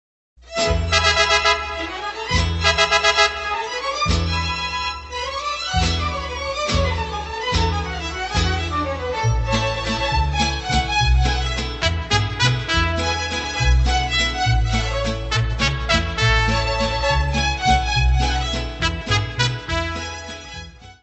: stereo; 12 cm + folheto